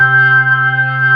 55o-org10-C3.wav